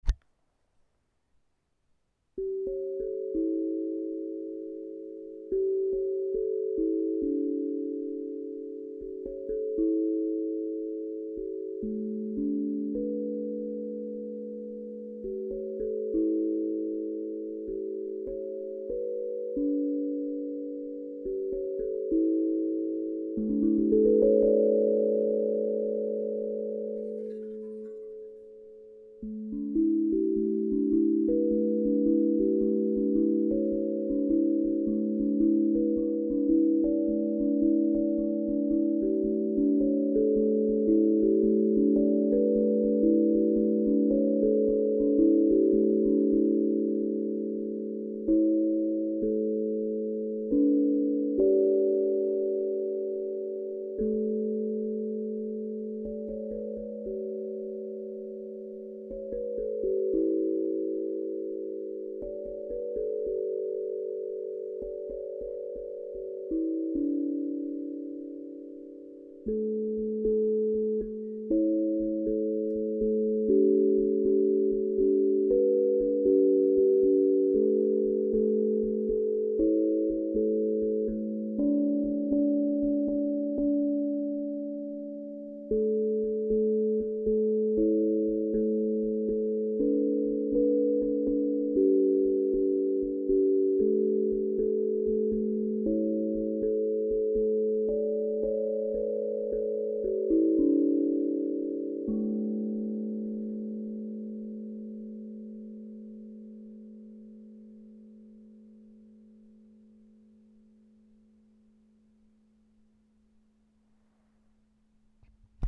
Tank drum Féline 432 Hz - Osb Drum